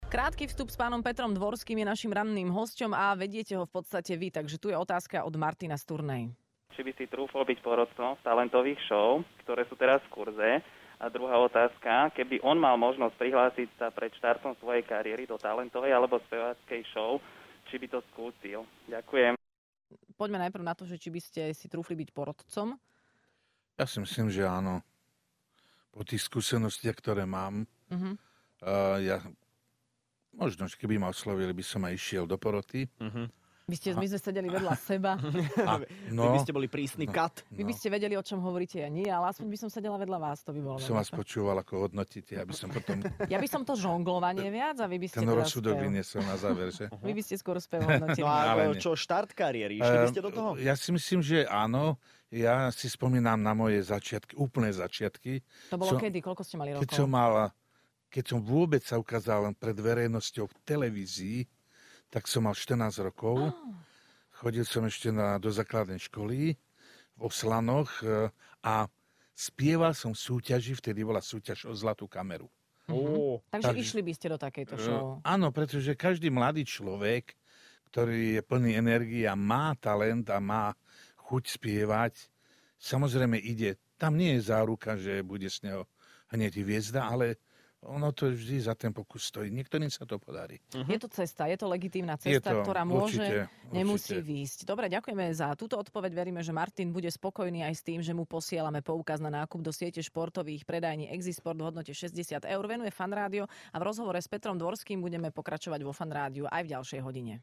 Hosťom v Rannej šou bol spevák Peter Dvorský.